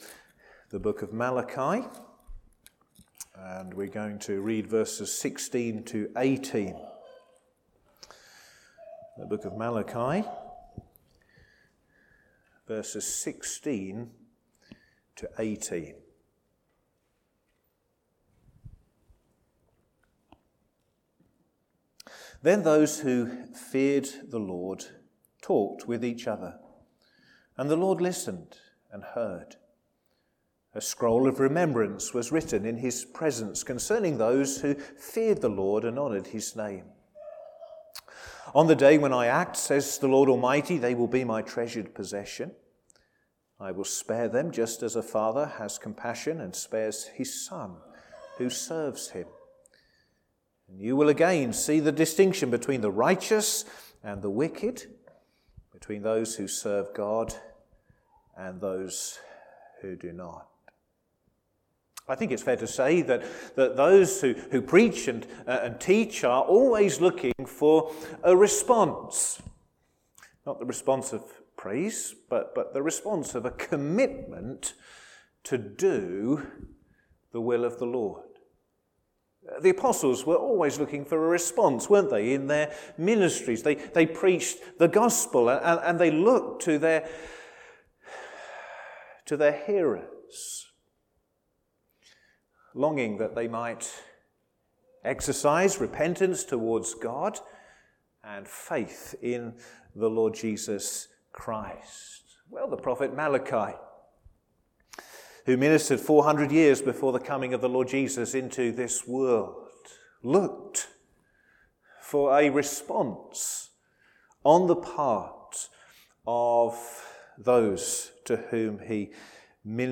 Sermons
Service Evening